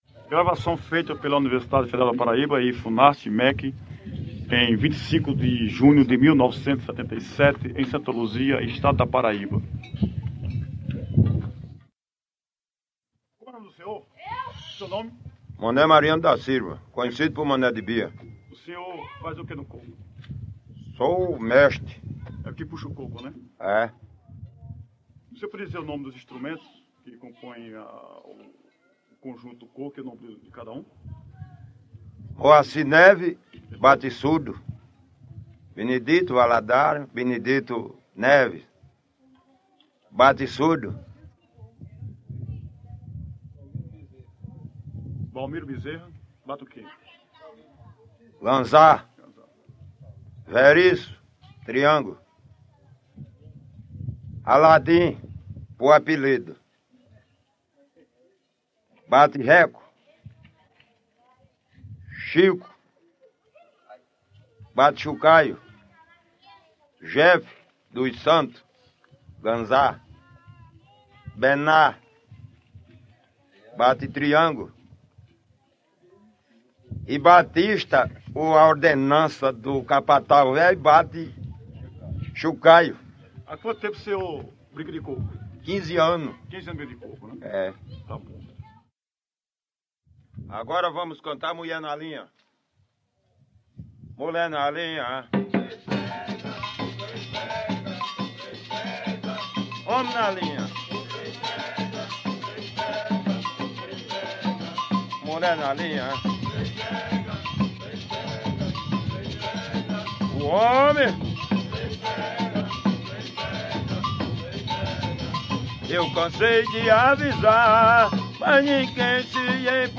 Cocos
São fortes as marcas da cultura negra nos cocos, especialmente nos dançados: os instrumentos utilizados, todos de percussão (ganzá, zabumba e tarol), o ritmo, a dança com umbigada ou simulação de umbigada e o canto com estrofes seguidas de refrão desenvolvido pelo solista e pelos dançadores.
Santa Luzia
Junho 1977 Coco de roda
banda cabaçal